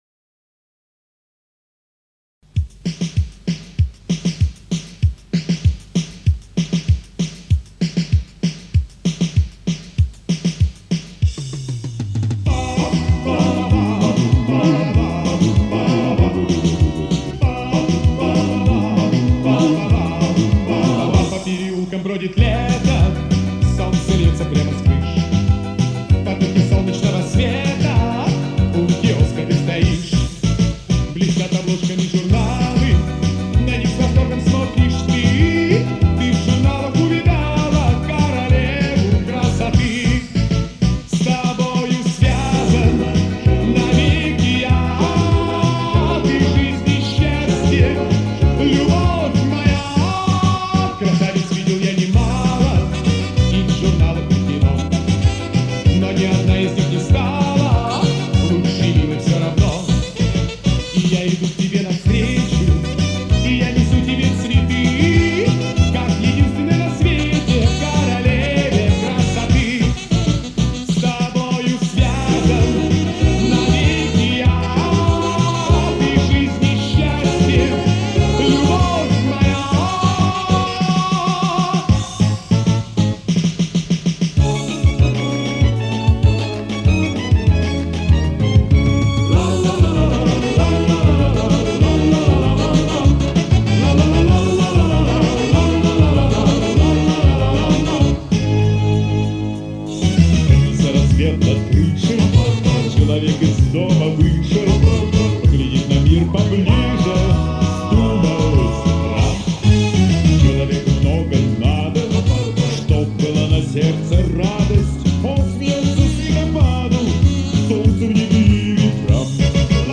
Первая часть попурри